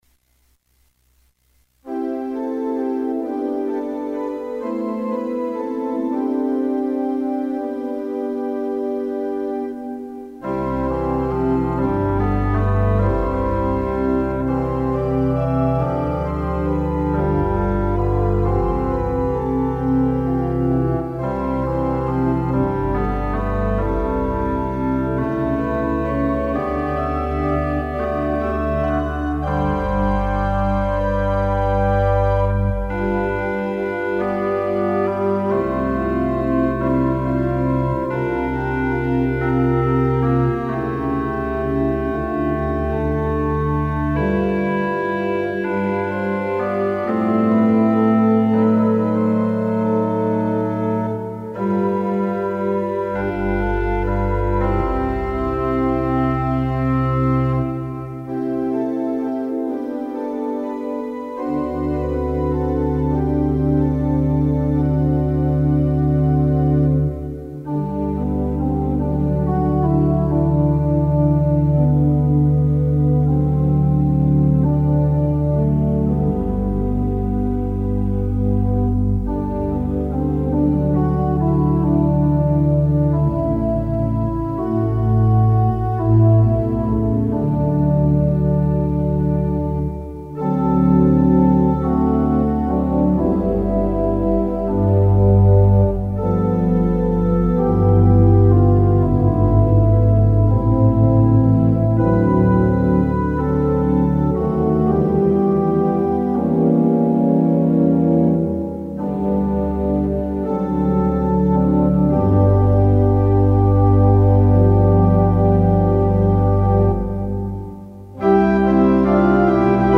It’s been running through my head as I’ve been thinking about this week in Vacation Bible School and it’s one that I wrote a hymn prelude for organ.